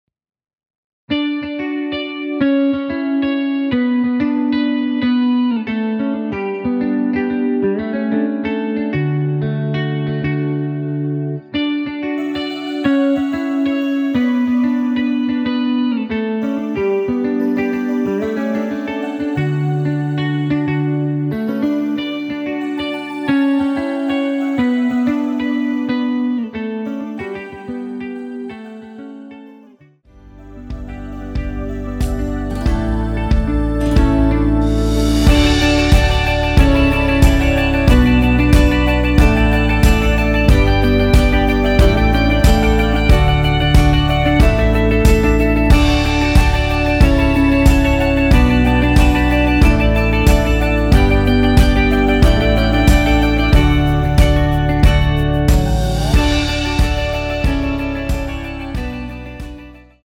원키에서(+5)올린 멜로디 포함된 MR입니다.(미리듣기 확인)
앞부분30초, 뒷부분30초씩 편집해서 올려 드리고 있습니다.